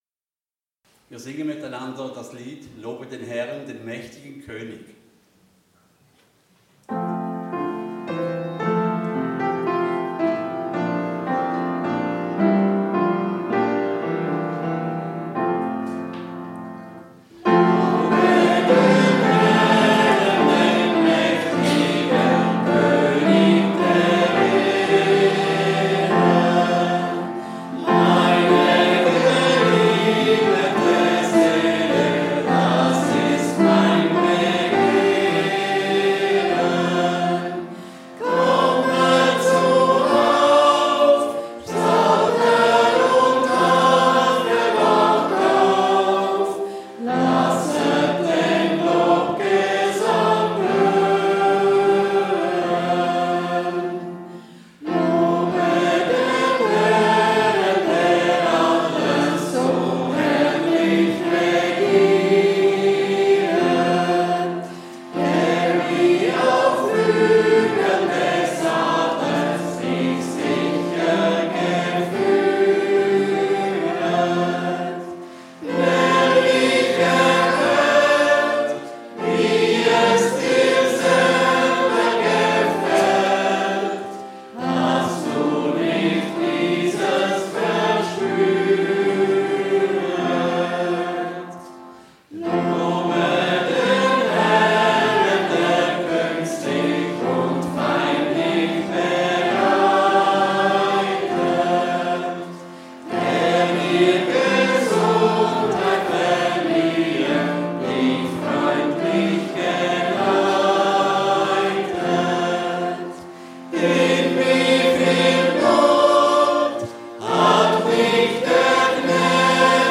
Viva Kirche Interlaken ~ Gottesdienst auf Radio BeO Podcast